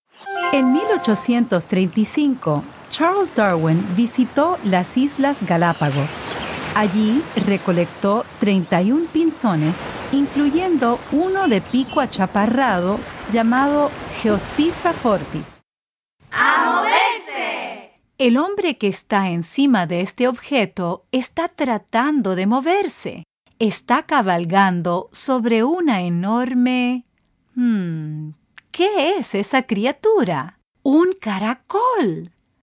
Audiolibros
Mis clientes describen mejor mi voz como amigable, chispeante, expresiva, agradable, cálida y entusiasta.